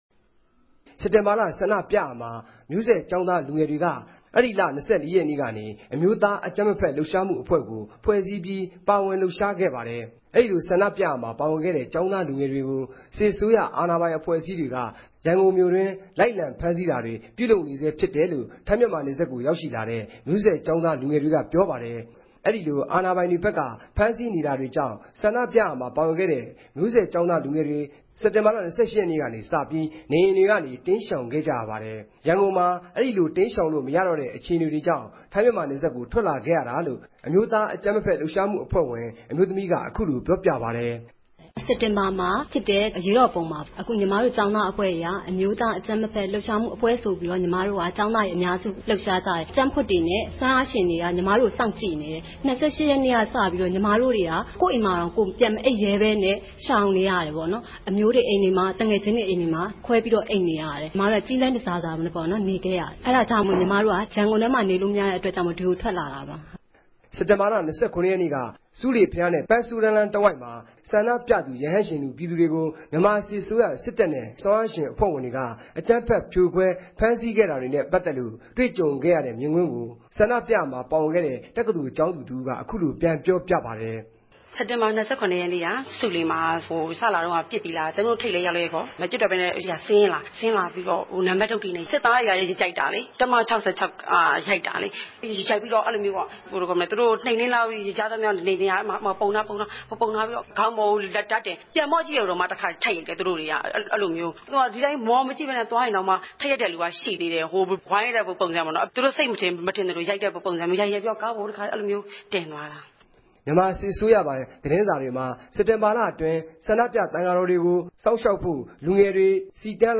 အဲဒီလို ထိုင်း-ူမန်မာနယ်စပ်ကို ရောက်ရြိလာတဲ့ မဵိြးဆက်သစ်ကေဵာင်းသားလူငယ်တေနြဲႛ တြေႚဆုံမေးူမန်း္ဘပီး